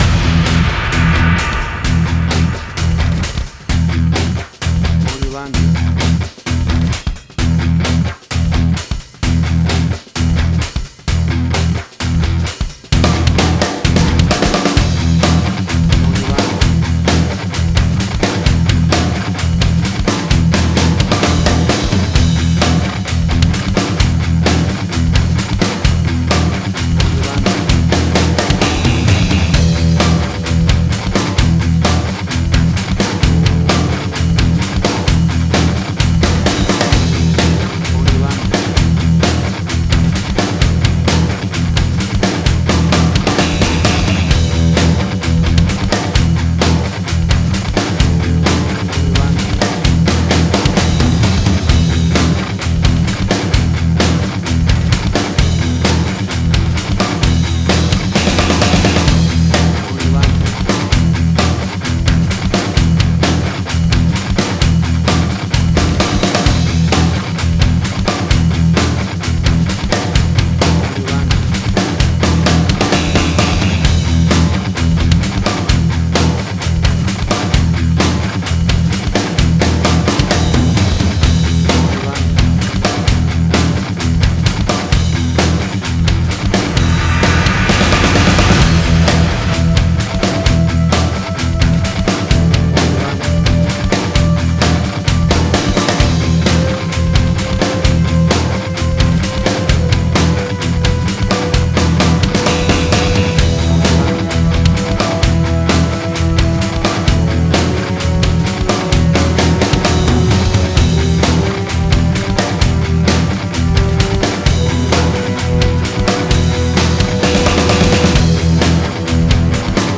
Hard Rock
Heavy Metal
Tempo (BPM): 130